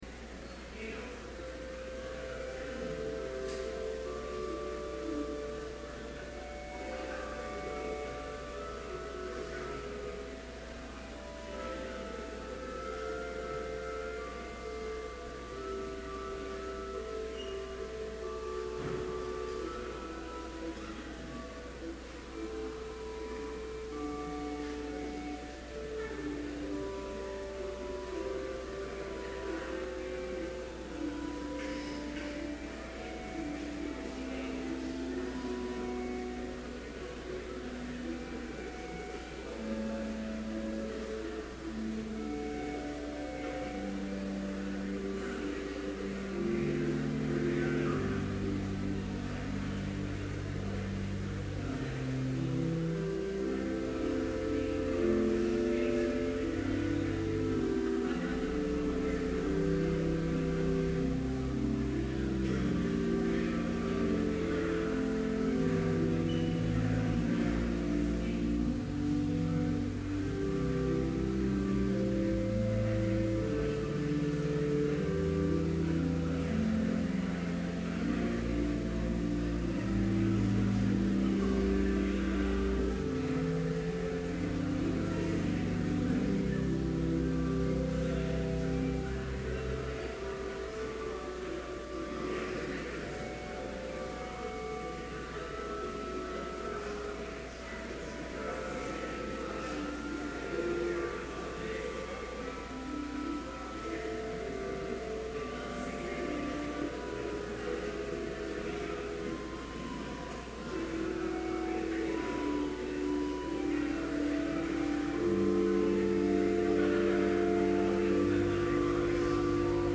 Complete service audio for Chapel - November 23, 2011